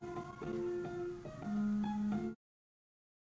电台
Tag: FM收音机 电台 AM